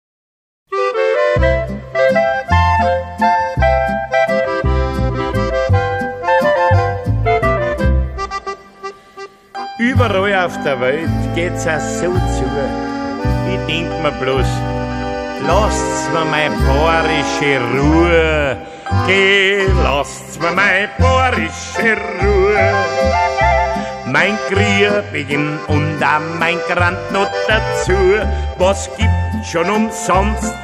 traditional Bavarian folk music